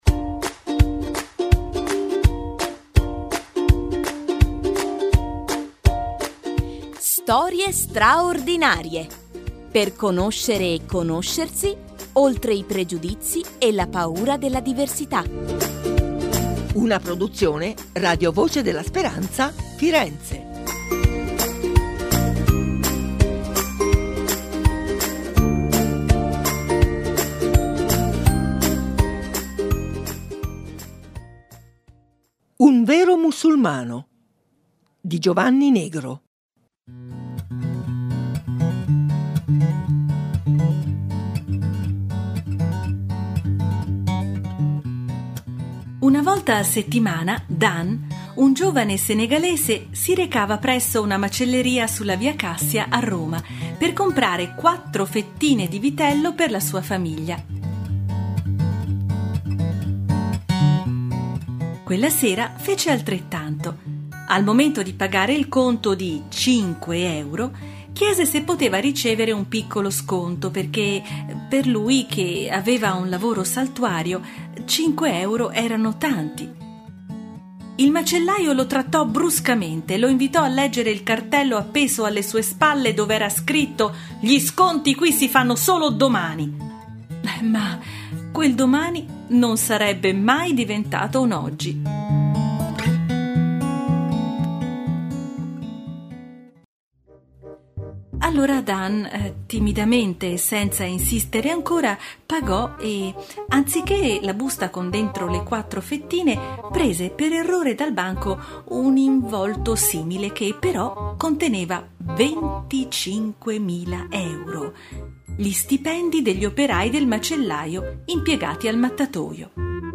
La redazione di Rvs Firenze presenta la collana “Storie stra-ordinarie – oltre il pregiudizio e la paura della diversità: storie per bambini… e non solo”.